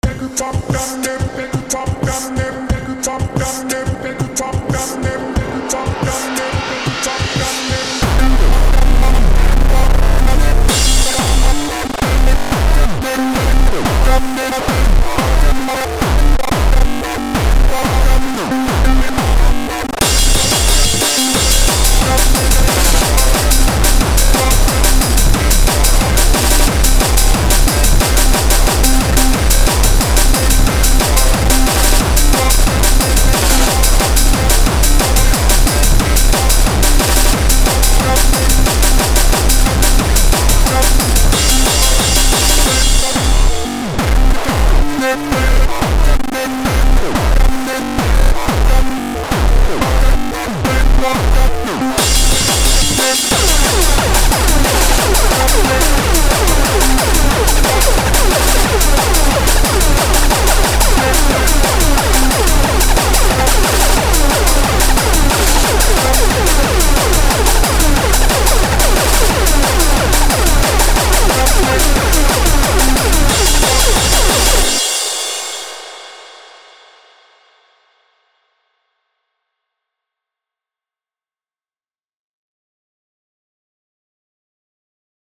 180bpm electronic edm breakcore breaks gabber hardcore rave